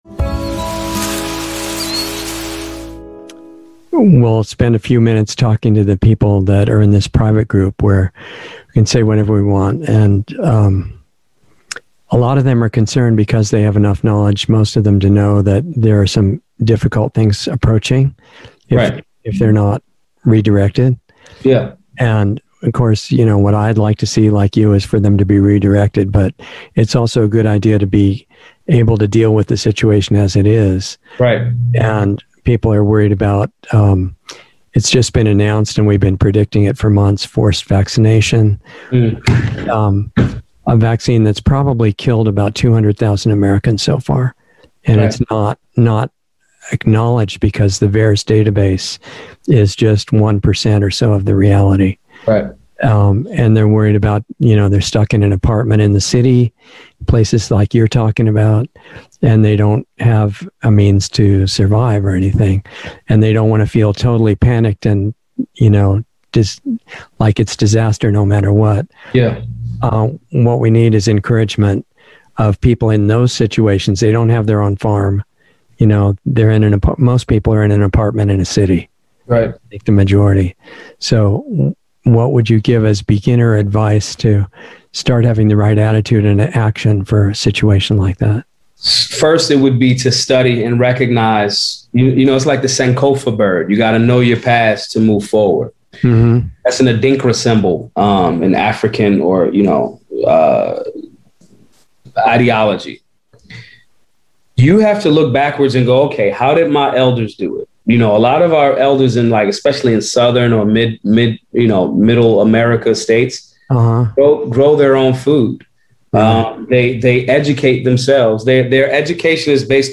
Insider Interview 7/15/21 0:11:48 הירשם 19 lostartsradio 4 שנים 79 צפיות תרומה אנא התחברו לתרומה MP3 להוסיף ל רוצה לראות את זה שוב מאוחר יותר?